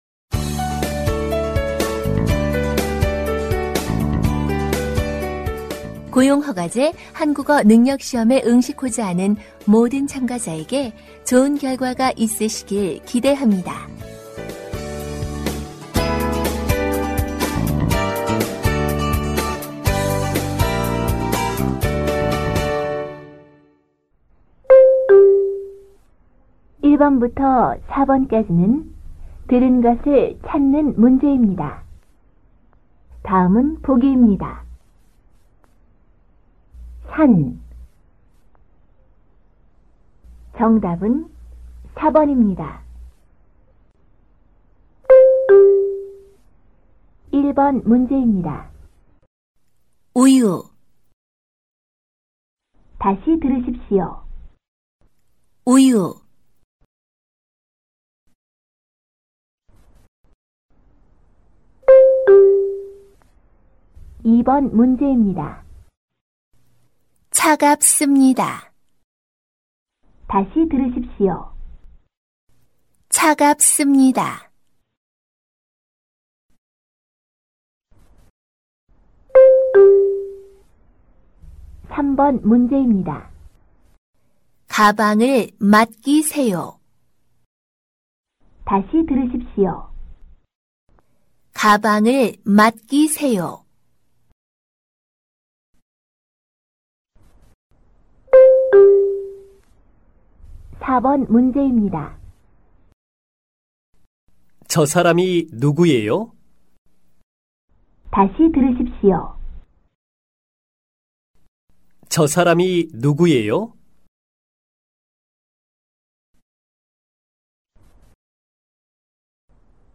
Phần Nghe Hiểu (듣기): Kéo dài từ câu 1 đến câu 25.
모든 듣기 문제는 두 번씩 들려 드립니다.